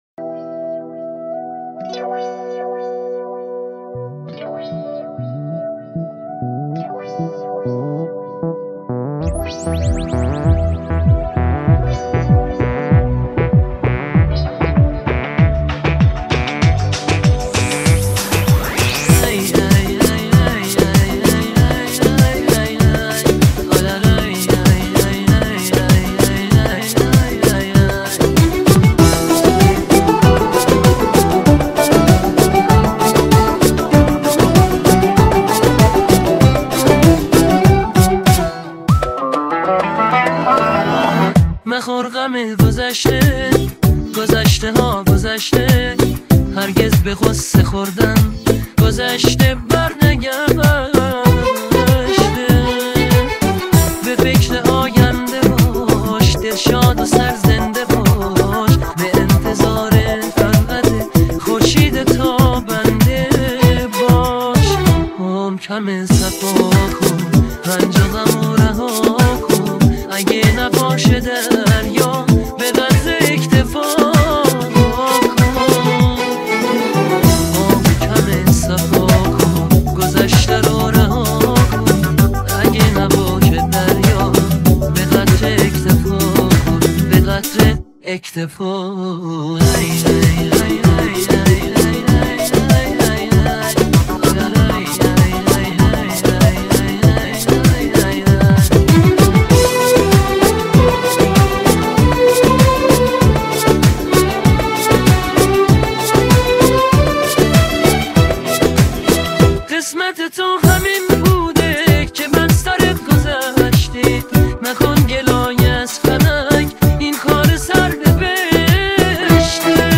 download new ai generated music